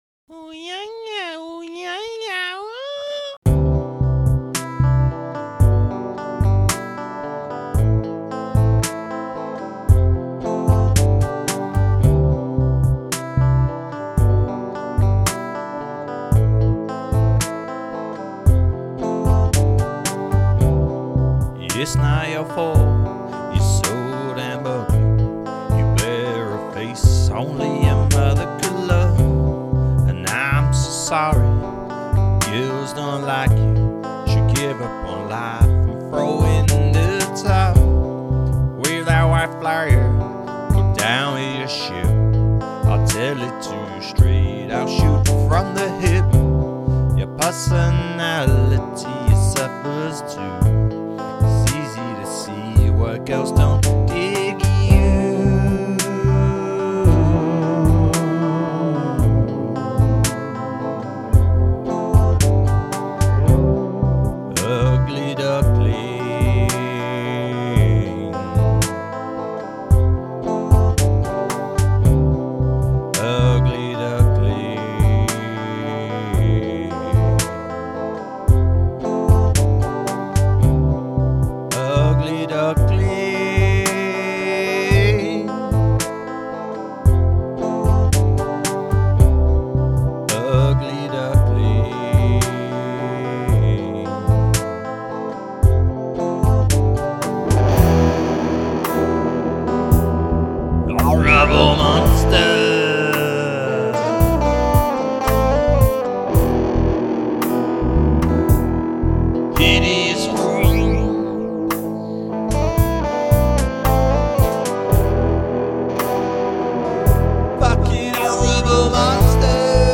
A soothing lullaby for the most part